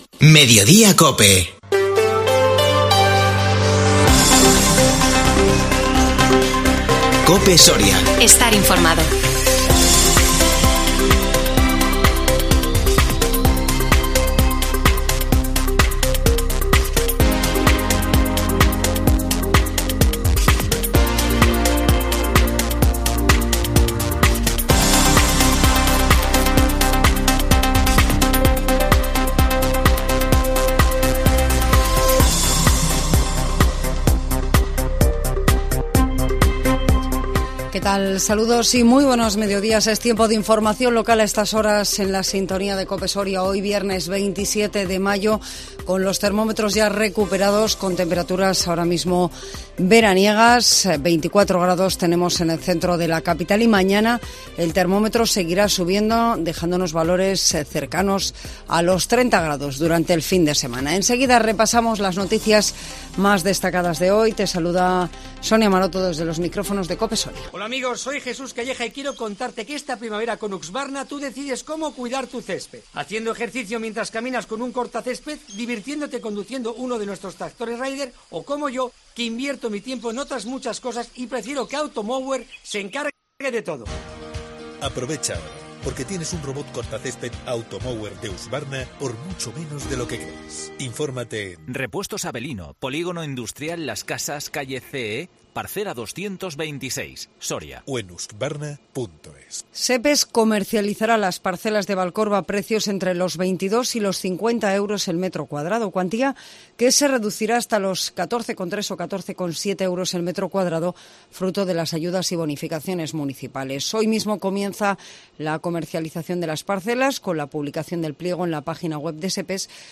INFORMATIVO MEDIODÍA COPE SORIA 27 MAYO 2022